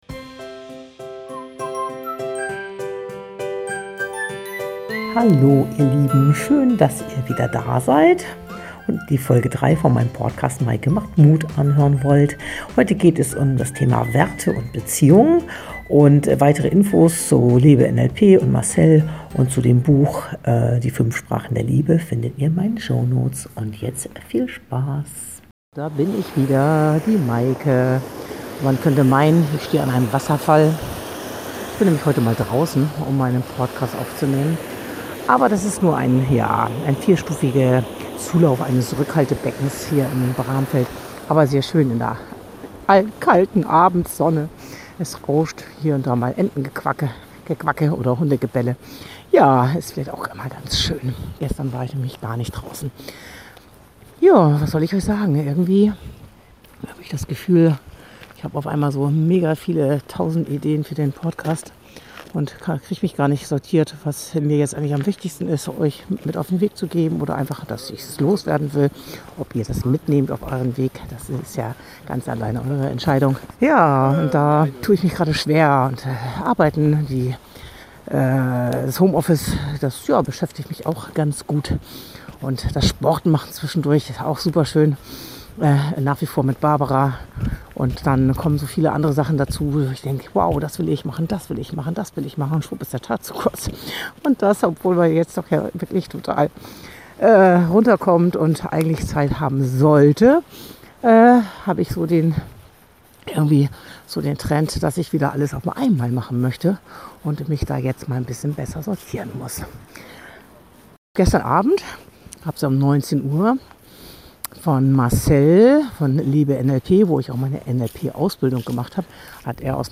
Leider knackt es hin und wieder, da mein Notebook offenbar gerade den Geist aufgibt- sorry dafür (ein neues Notebook ist bereits bestellt!).